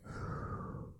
Exhale.wav